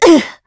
Isolet-Vox_Damage_kr_02.wav